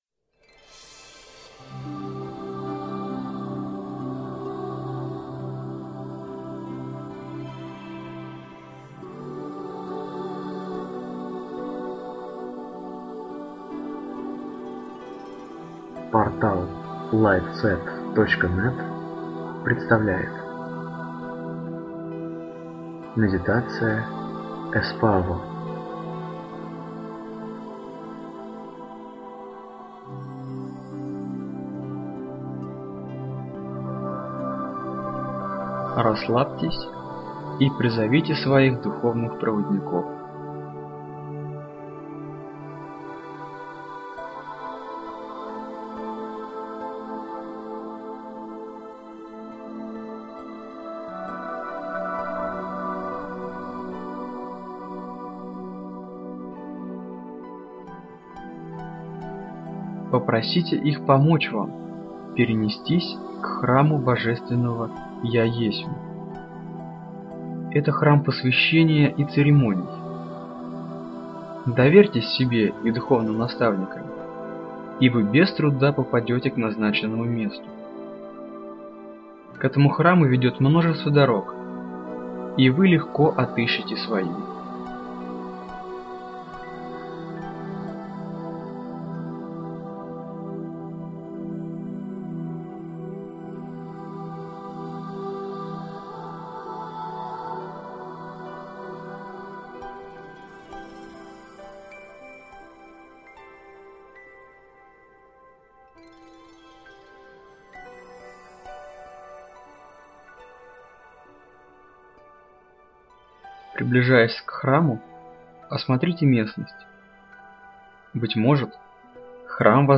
Рэйки - Возрождение - Медитация Эспаво - Путешествие в Храм «Я Есмь»